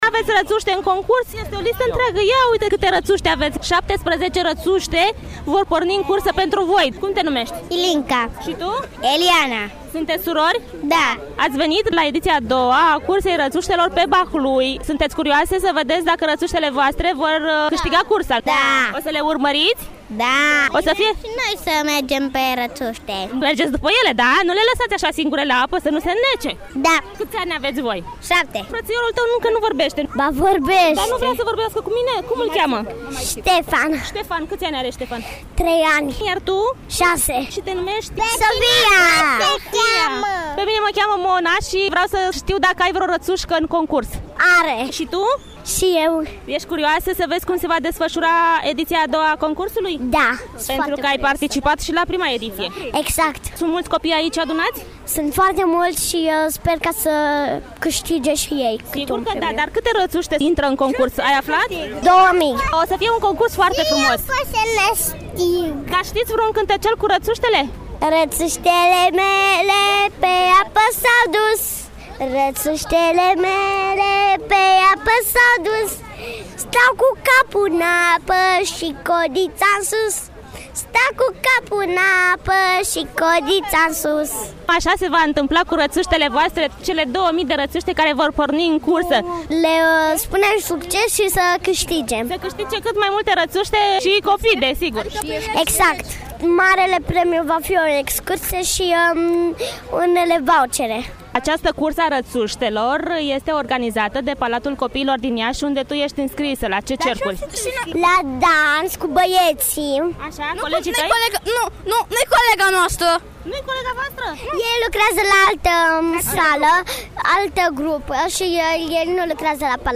N-a lipsit nimic din cele specifice marilor concursuri, nici spectatorii, mulți la număr.